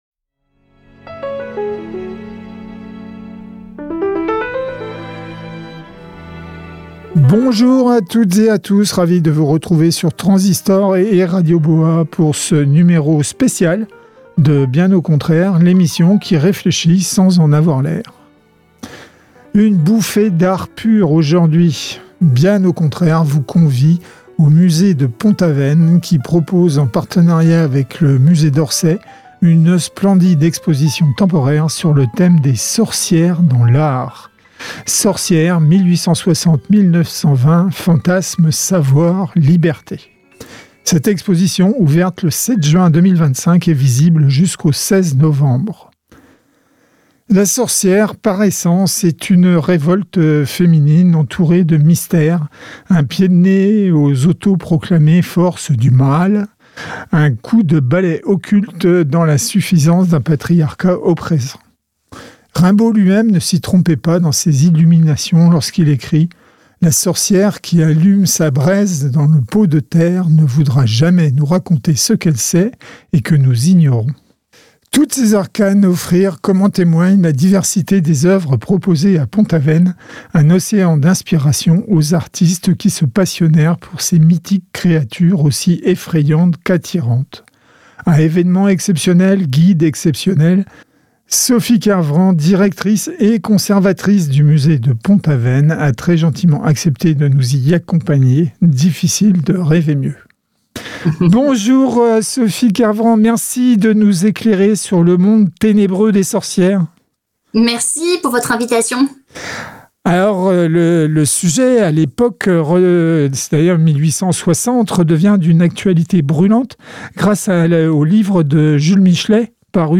Pour cette émission spéciale, nous vous emmenons au musée de Pont-Aven (Finistère). En plus de sa remarquable collection permanente, il propose depuis le 7 juin 2025 – et jusqu’au 16 novembre – une exposition exceptionnelle par la richesse des œuvres présentées et par son thème : Sorcières (1860-1920) : fantasmes, savoirs, liberté.